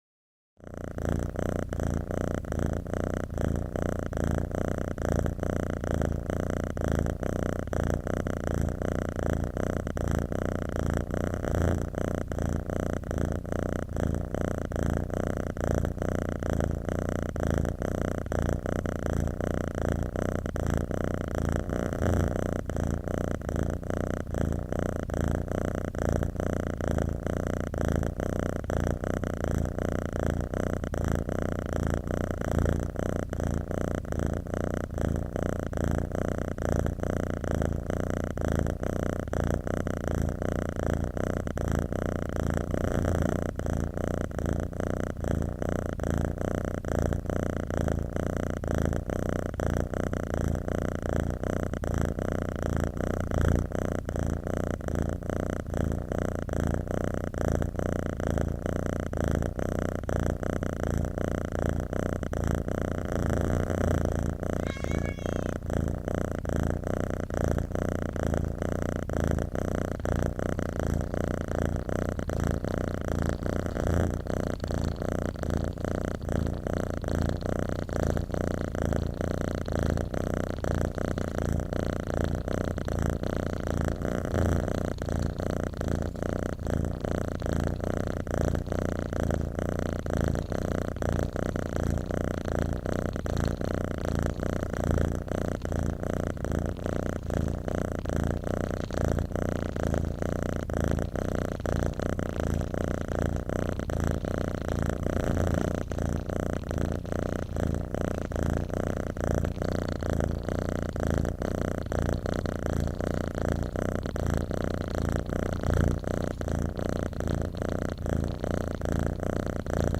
Long Cat Purring